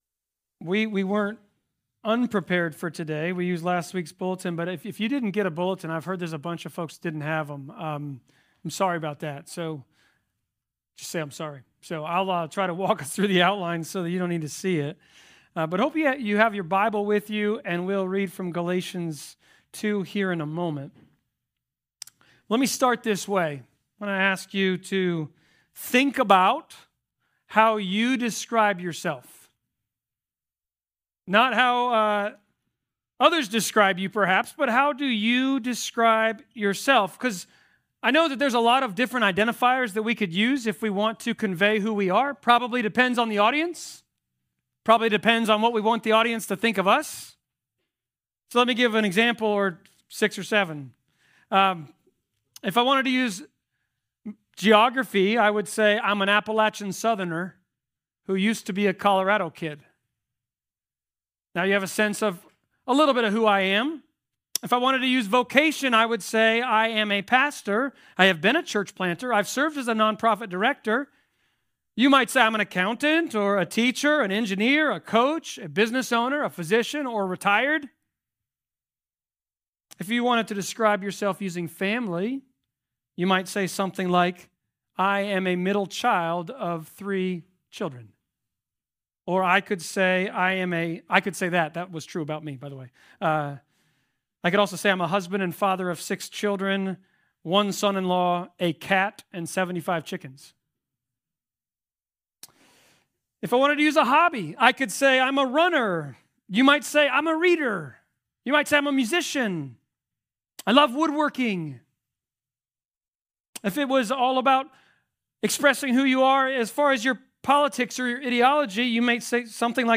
Galatians Passage: Galatians 2.15-21 Service Type: Sermons « Christ Community Church